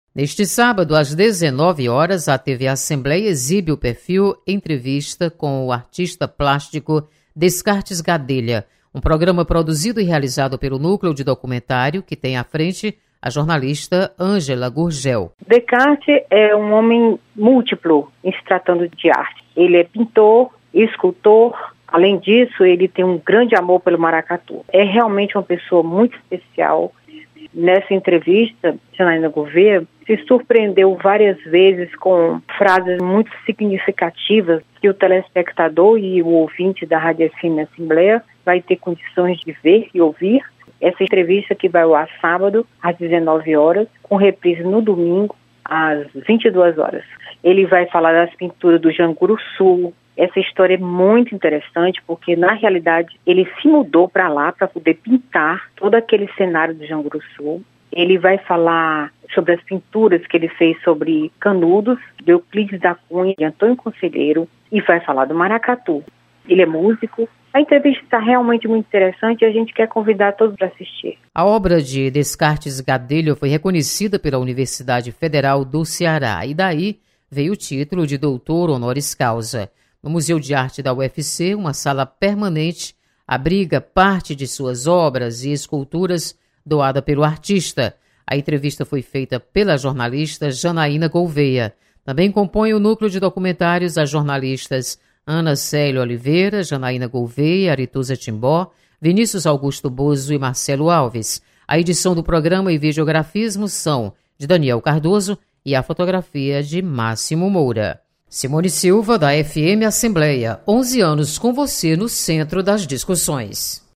História do artista plástico Descartes Gadelha é tema de documentário produzido pela TV Assembleia.